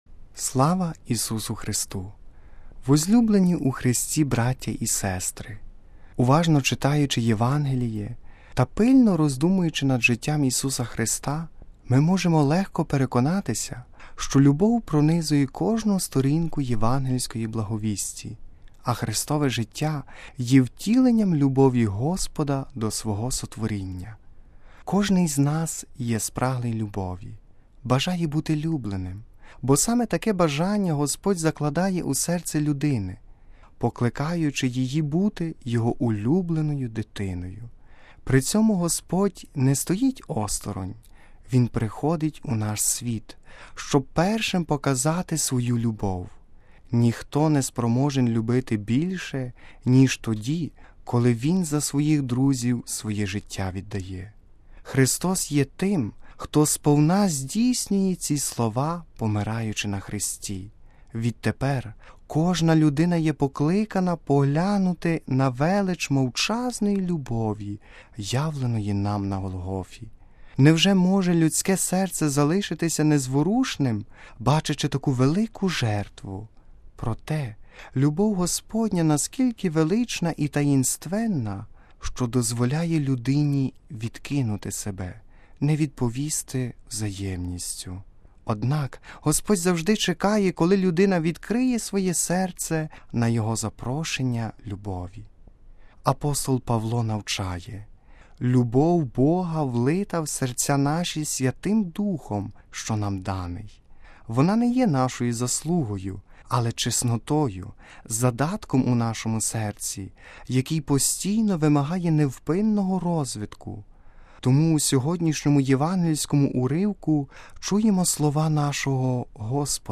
Співали студенти богослови Папської Української Колегії святого Йосафата.
Пропонуємо вашій увазі звукозапис цієї Святої Літургії: Проповідь: